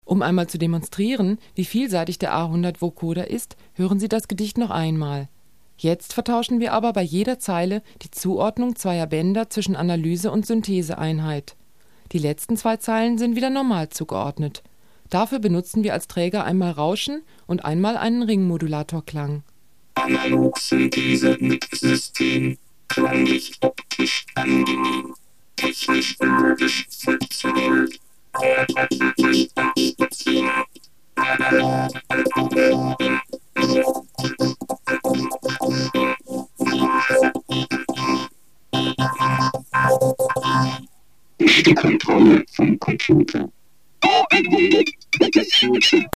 A vokóderes szöveg a következő: